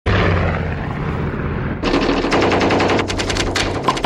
• MACHINE GUN FIRE WITH PLANE.mp3
machine_gun_fire_with_plane_638.wav